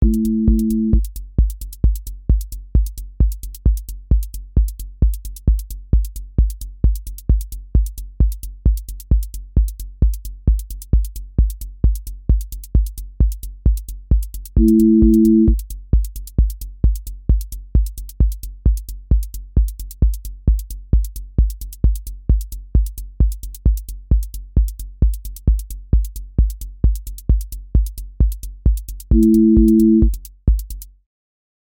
techno pressure with driven motion
• voice_kick_808
• voice_hat_rimshot
• voice_sub_pulse
• tone_brittle_edge
• motion_drift_slow
Techno pressure with driven motion